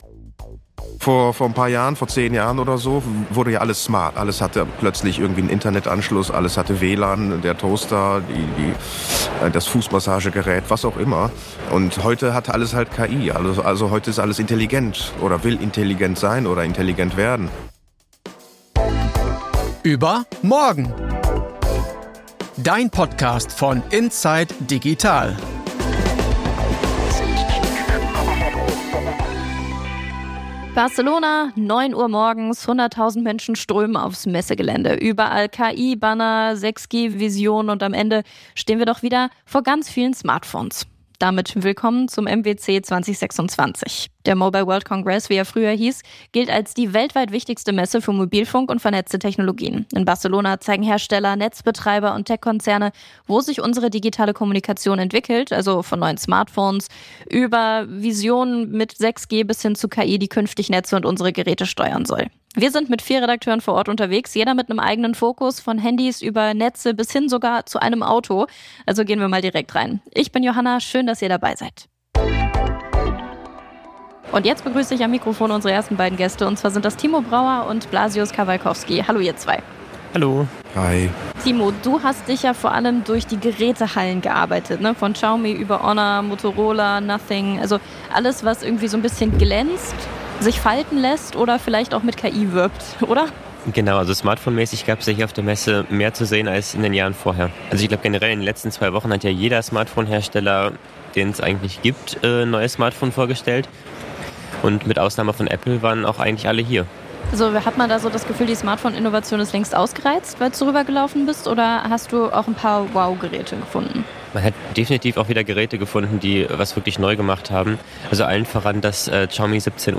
Wir sind mit vier Redakteuren vor Ort und sprechen über die spannendsten Geräte (inklusive Kamera-Highlight von Xiaomi und neue Foldables), über KI-Funktionen, die plötzlich proaktiv mitdenken, und darüber, was davon echte Hilfe ist und was eher Marketing. Außerdem: KI im Netz für Großevents, ein Telekom-Call-Agent, Satelliten gegen Funklöcher - und warum Smart Glasses zwar faszinieren, das Handy aber (noch) nicht ersetzen.